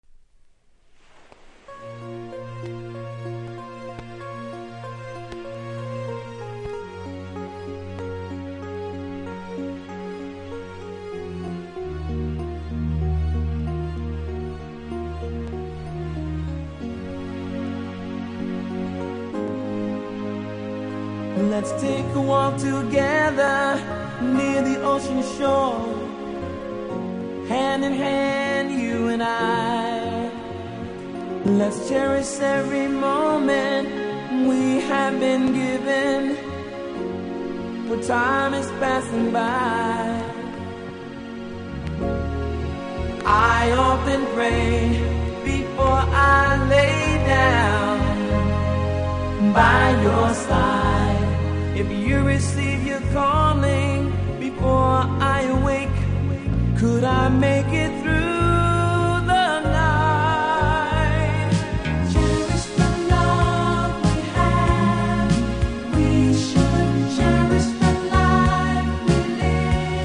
「私達の愛を愛しもう、人生を大切に生きよう」とのラブソング♪
序盤だけ少しノイズありますので試聴で確認下さい。